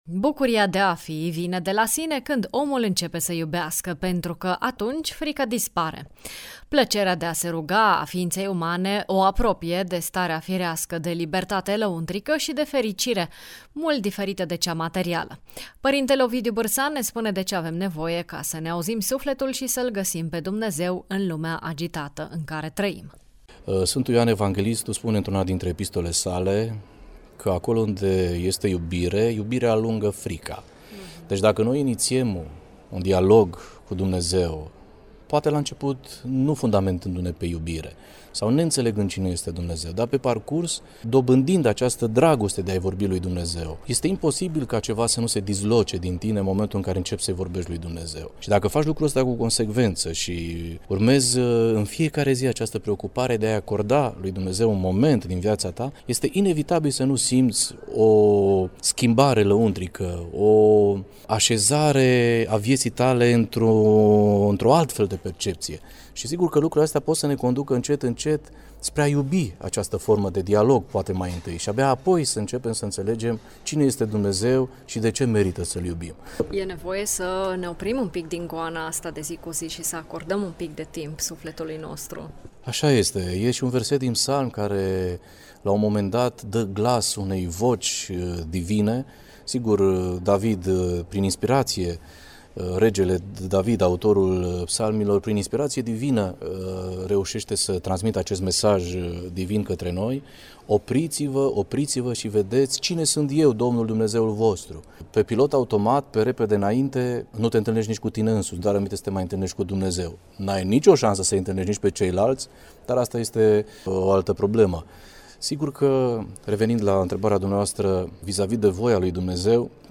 Preot